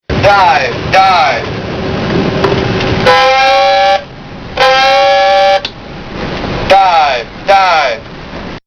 And because, good as it is, it’s only lumière without son, I’ve got an extra treat for you: a clip from an authentic (US) submarine
(You might want to turn your volume down.)
688dive3.mp3